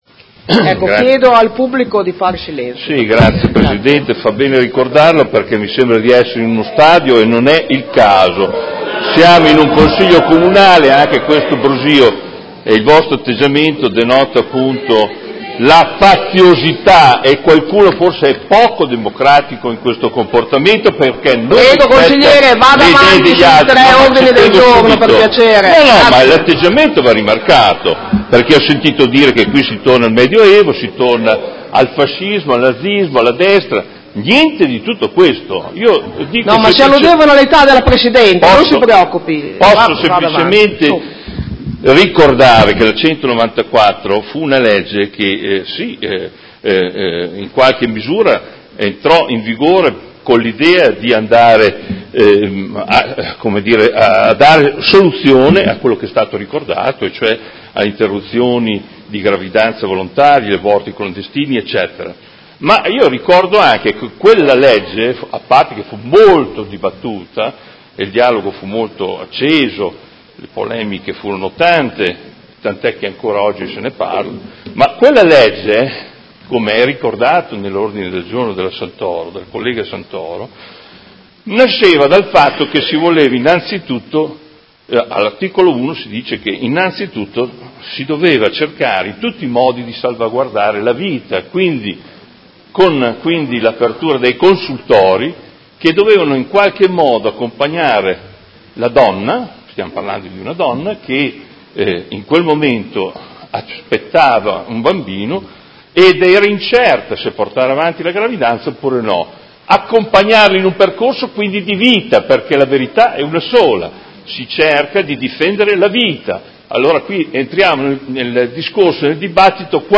Seduta del 07/02/2019 Dibattito congiunto su: Mozione nr. 161094 presentata dal Gruppo Articolo 1-MDP – PerMeModena avente per oggetto: Condanna e forte preoccupazione per l’attacco alla Legge 194 e per il sempre maggiore numero di obiettori che mette a rischio la sua applicazione e Ordine del Giorno nr. 162890 presentato dalla Consigliera Santoro (Lega Nord) avente per oggetto: Iniziative per la prevenzione dell’aborto e a sostegno della maternità e Ordine del Giorno nr. 165857 presentato dai Consiglieri Venturelli, Liotti, Arletti, Pacchioni, Bortolamasi, Fasano e Di Padova (PD) avente per oggetto: Piena applicazione della L. 194/78 e potenziamento della rete dei consultori familiari